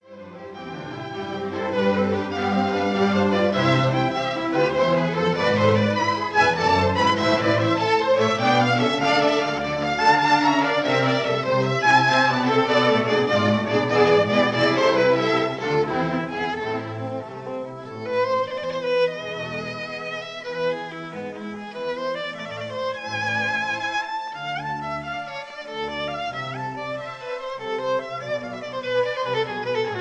conductor
historic 1936 recording